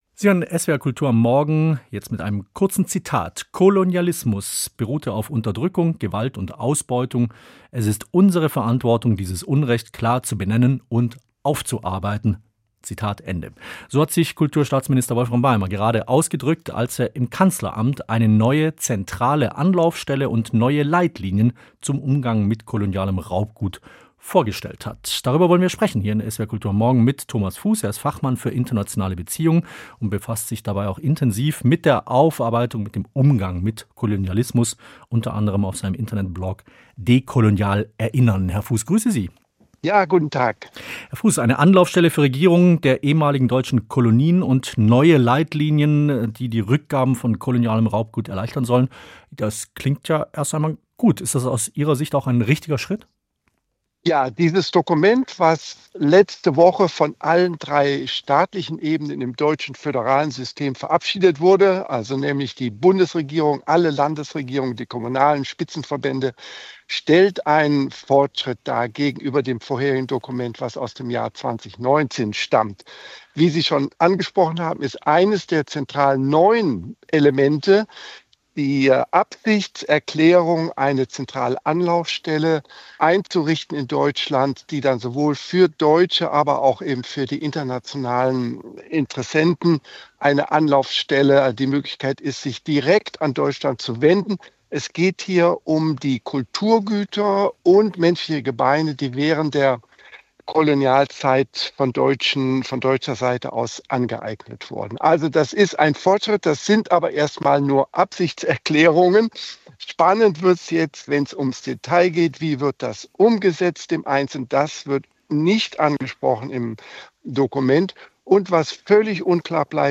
Interview mit